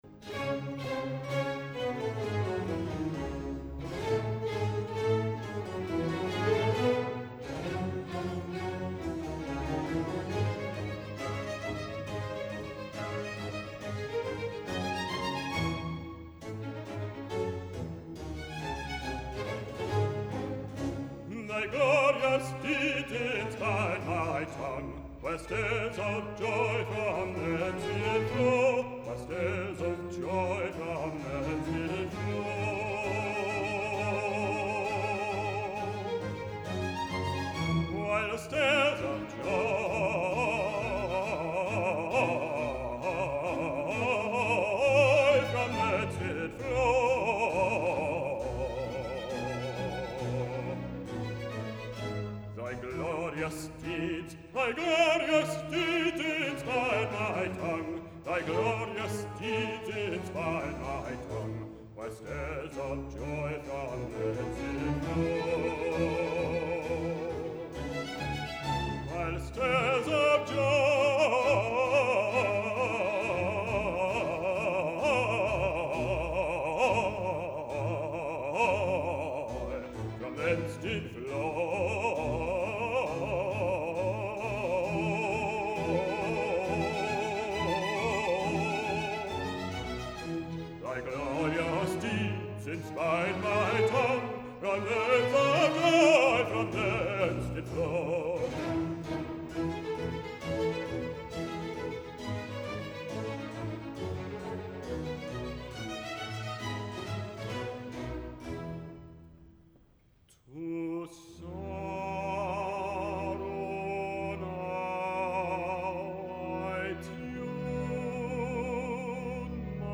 Recorded live in concert with Philharmonia Baroque Orchestra in Berkeley, California